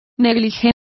Complete with pronunciation of the translation of negligee.